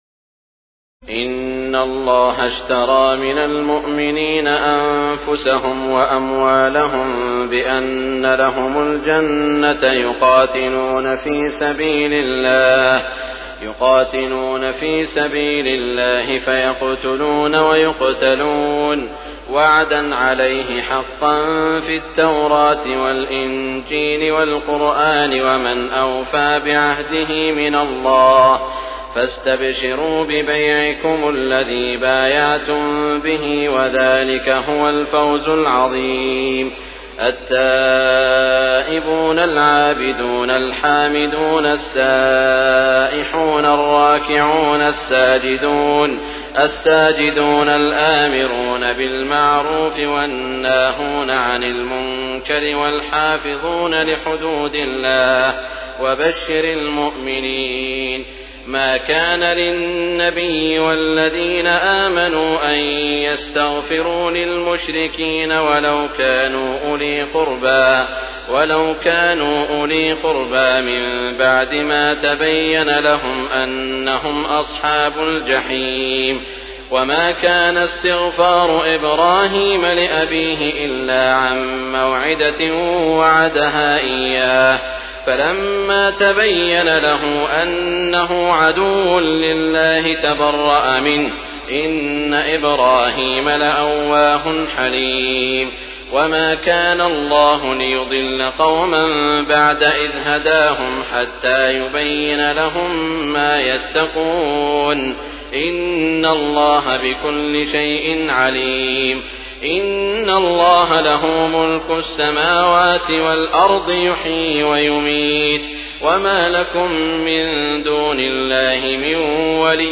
تراويح الليلة الحادية عشر رمضان 1418هـ من سورتي التوبة (111-129) و يونس (1-60) Taraweeh 11 st night Ramadan 1418H from Surah At-Tawba and Yunus > تراويح الحرم المكي عام 1418 🕋 > التراويح - تلاوات الحرمين